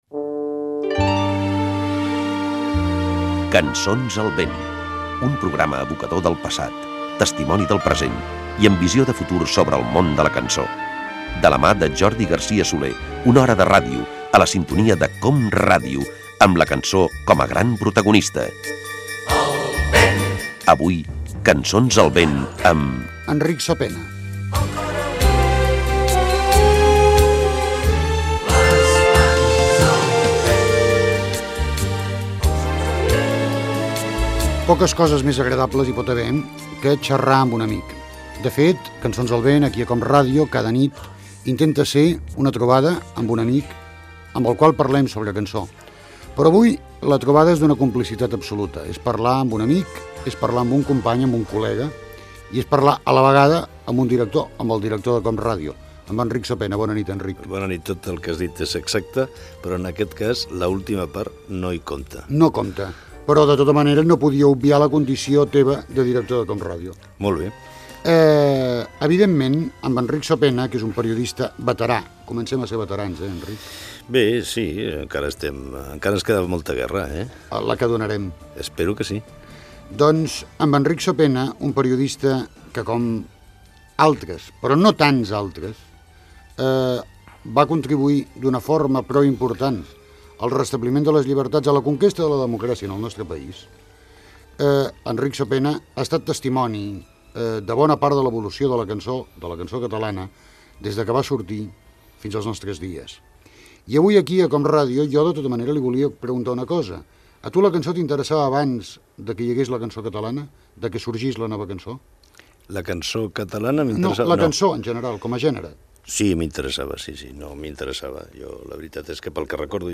Careta, presentació i fragment d'una entrevista amb la música que escoltava el periodista Enric Sopena, aleshores director general de COM Ràdio.
Musical